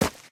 Divergent / mods / Footsies / gamedata / sounds / material / human / step / t_earth2.ogg